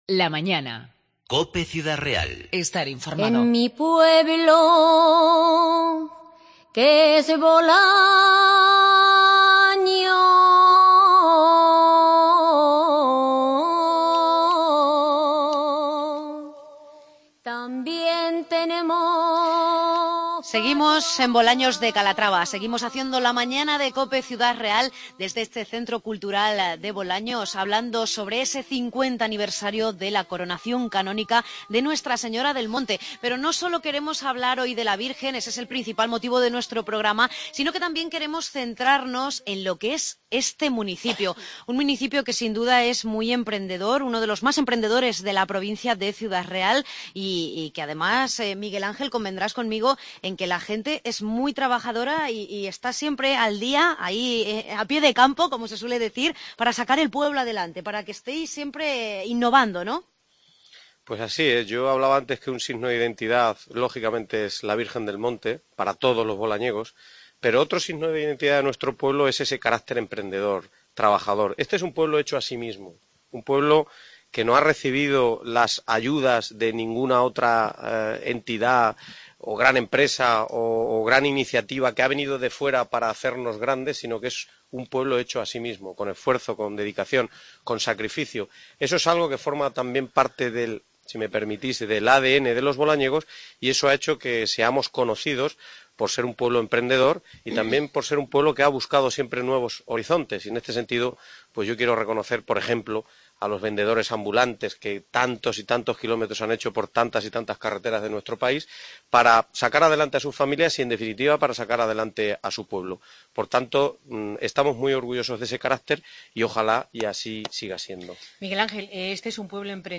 La Mañana de COPE Ciudad Real desde Bolaños 20-5-16 segunda parte